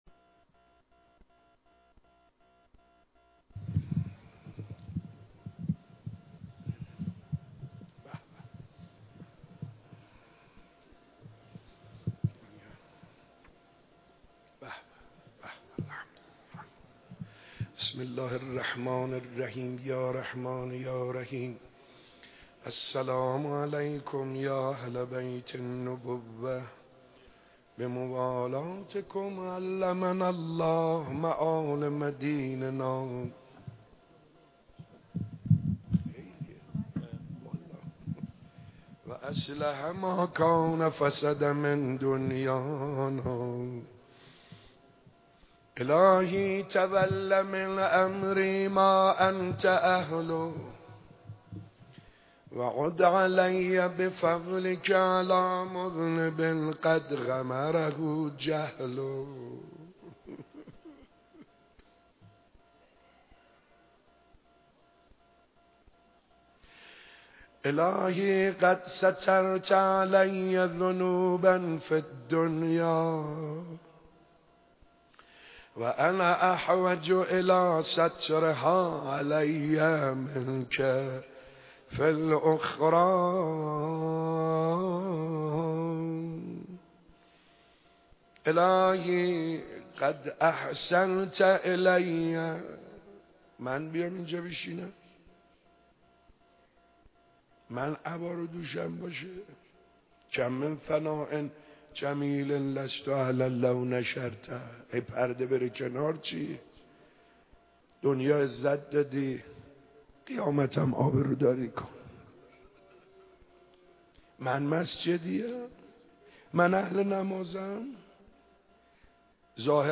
مراسم عزاداری پنج شب آخر ماه محرم الحرام /مسجد جامع امام سجاد علیه السلام
صوت مداحی